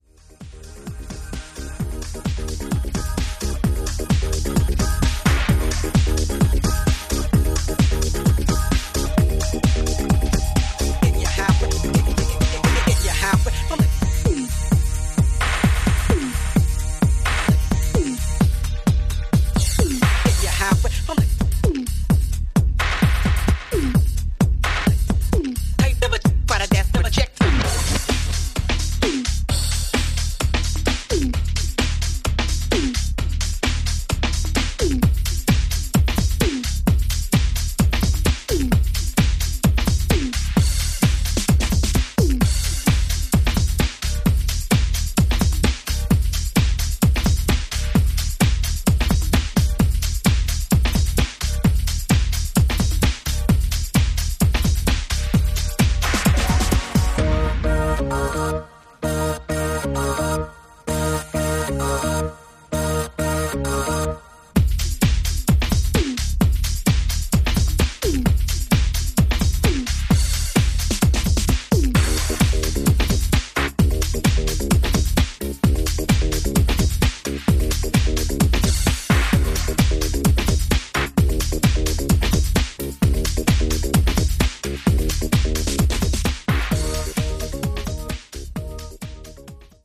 ソリッドにシェイプされており使い勝手抜群！